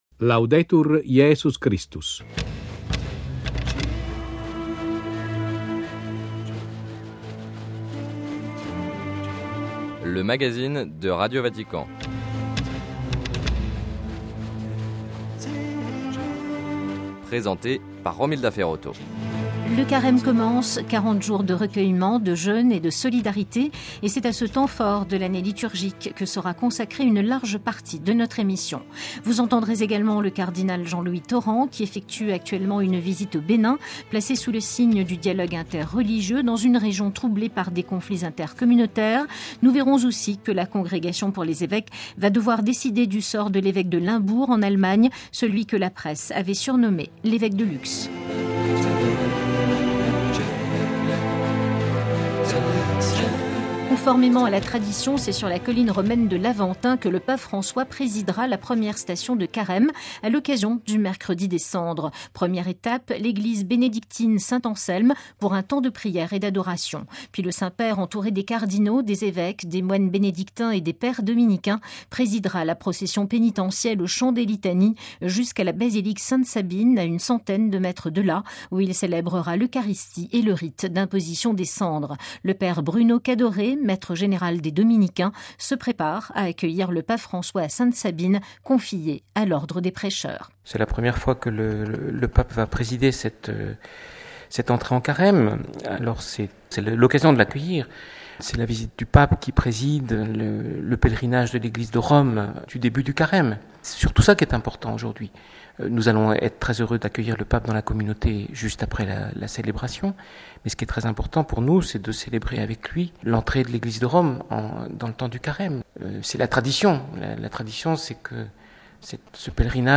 - Campagne de Carême des évêques brésiliens contre la traite des êtres humains. - Entretien avec le cardinal Jean-Louis Tauran, président du Conseil pontifical pour le dialogue interreligieux, qui effectue une visite au Bénin.